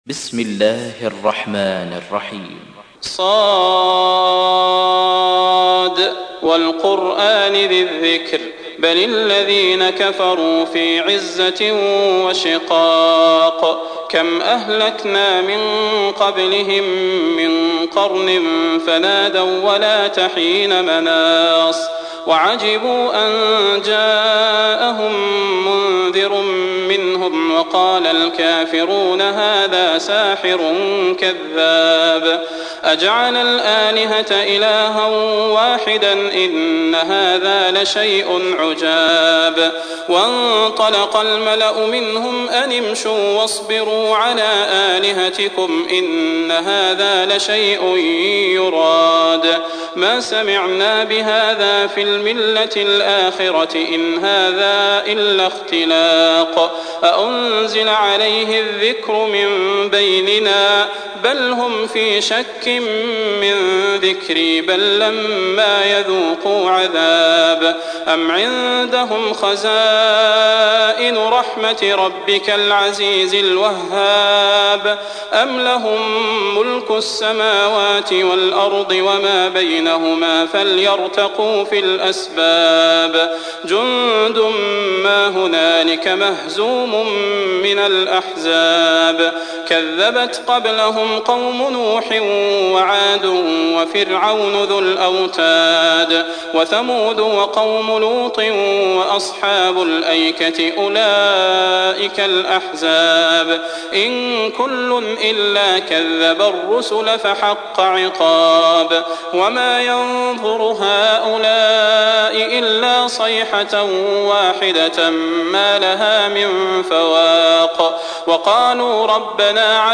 تحميل : 38. سورة ص / القارئ صلاح البدير / القرآن الكريم / موقع يا حسين